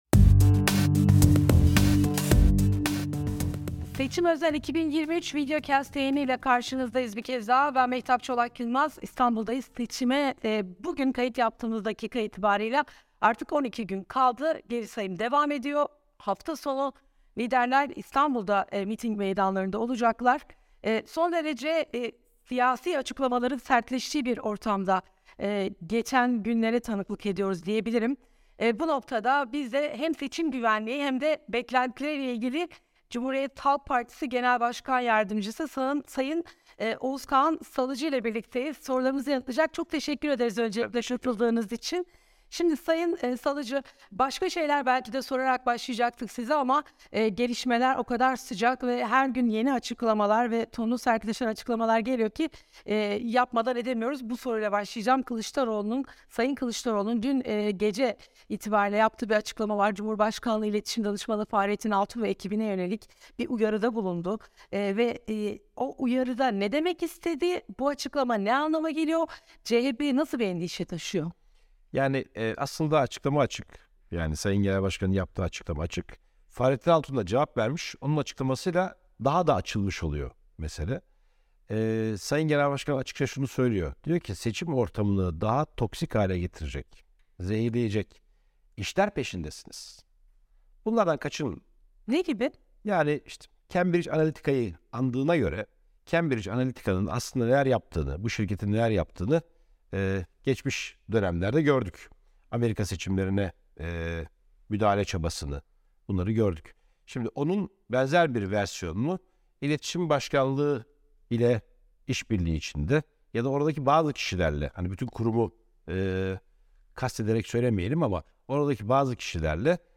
Muhalefetin seçime günler kala iktidardan manipülasyon adımları geleceği iddiasından, seçim güvenliğine, merak edilen birçok soru var. Seçim 2023 Videocast serisinde VOA Türkçe’nin sorularını CHP Genel Başkan Yardımcısı Oğuz Kaan Salıcı yanıtladı